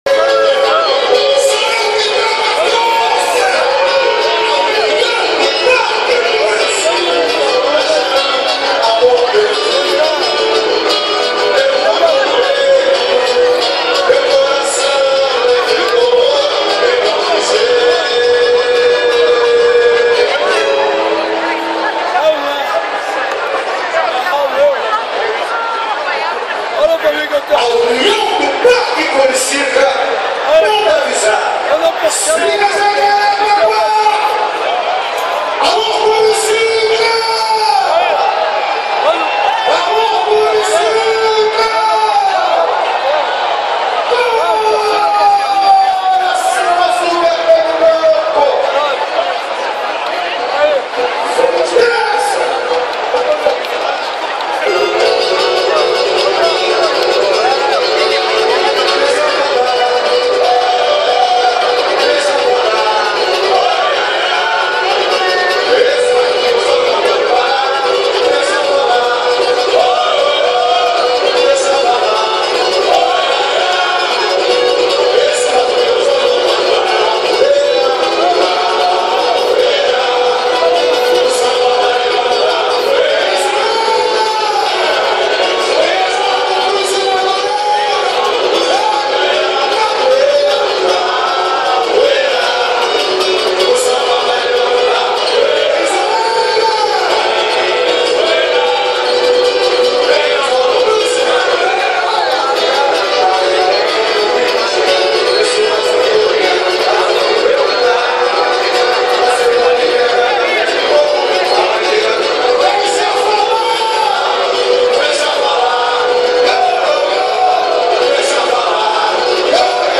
Cantei e vibrei o tempo inteiro com o “sacode” que a escola fez na avenida.